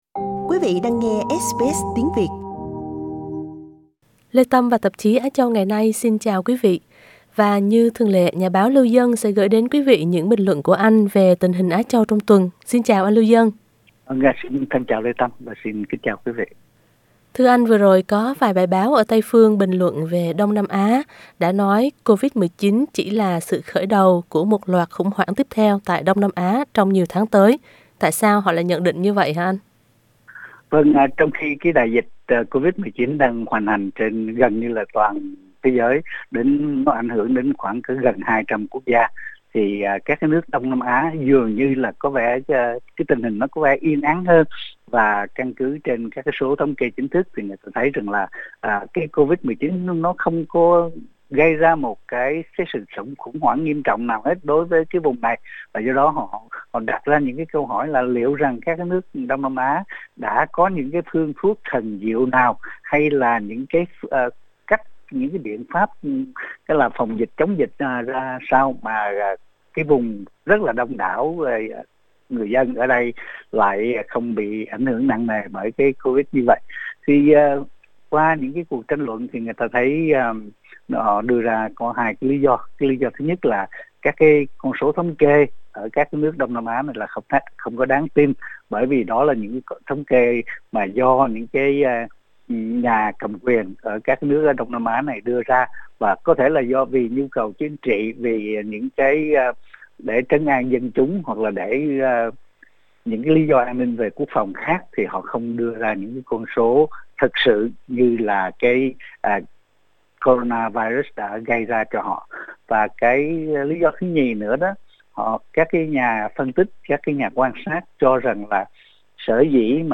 hội thoại